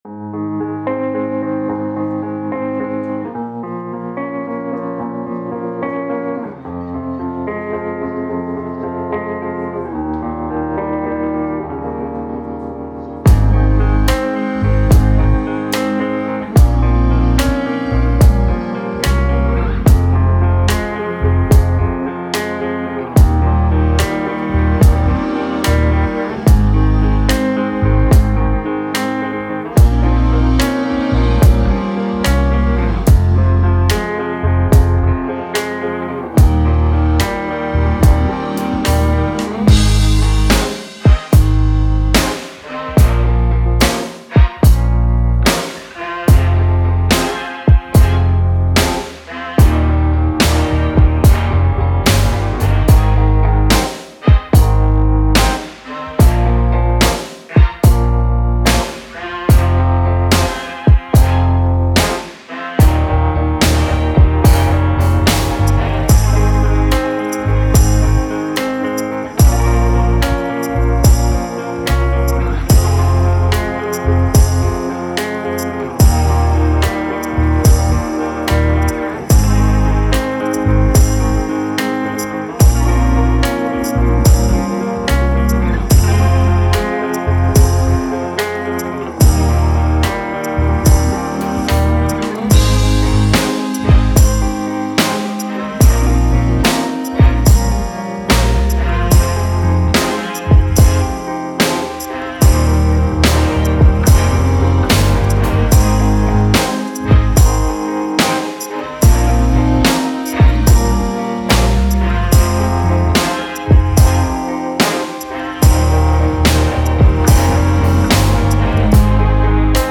Doo Wop
G#